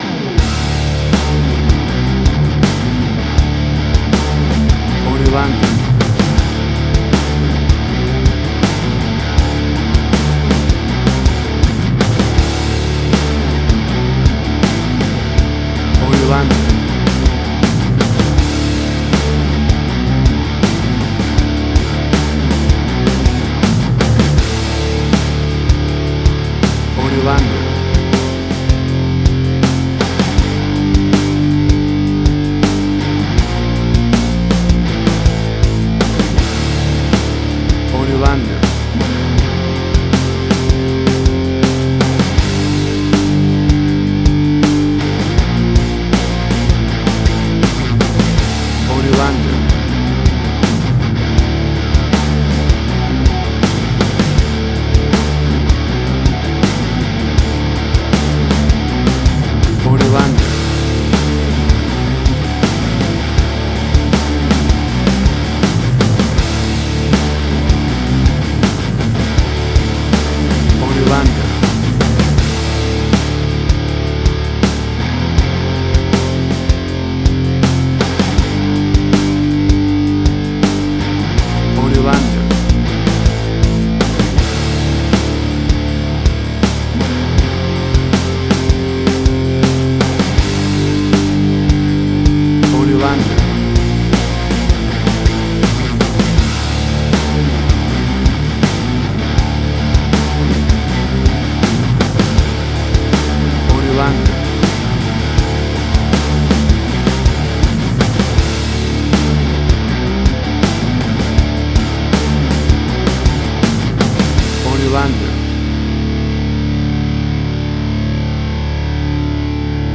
Hard Rock
Heavy Metal
Tempo (BPM): 80